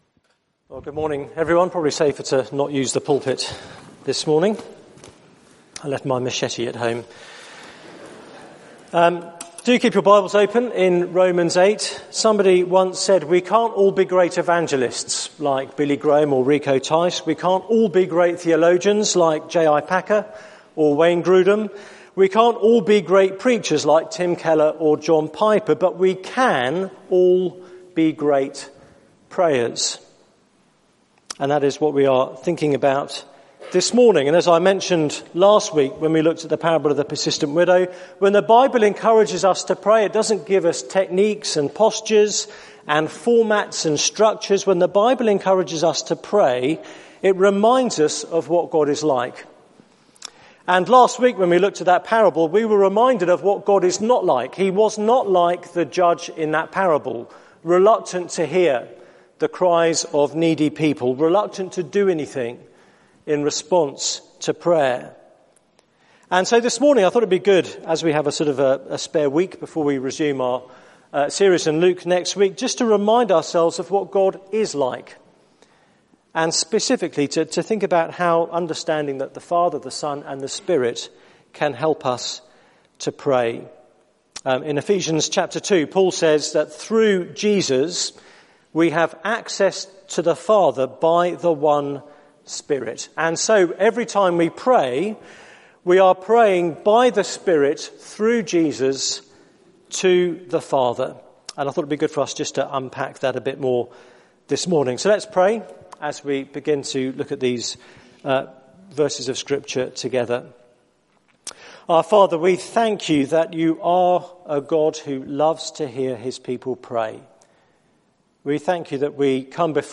Media for 9:15am Service on Sun 30th Aug 2015 09:15 Speaker
Theme: Praying with the Trinity Sermon Search the media library There are recordings here going back several years.